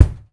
04B KICK  -L.wav